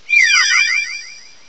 sovereignx/sound/direct_sound_samples/cries/hattrem.aif at master